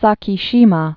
(säkē-shēmä, sä-kēshē-mä)